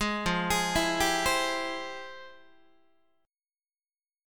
FmM9 Chord
Listen to FmM9 strummed